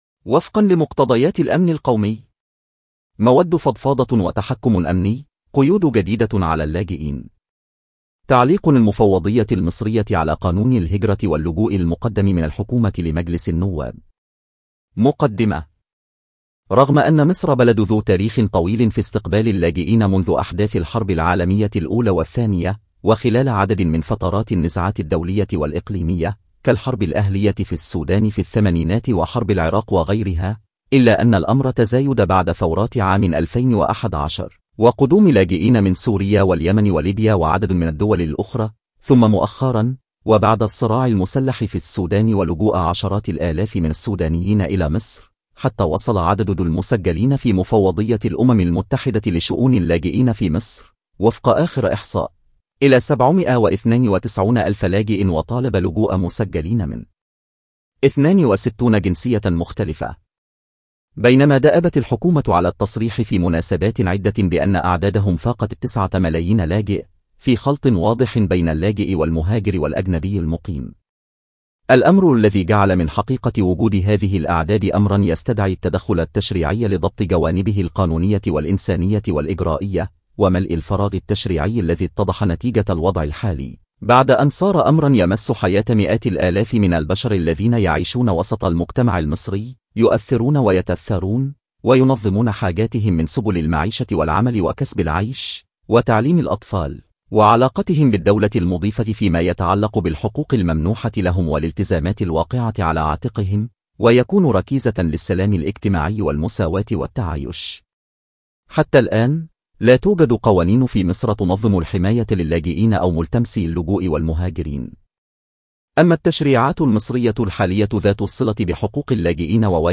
تنويه: هذه التسجيلات تمت باستخدام الذكاء الاصطناعي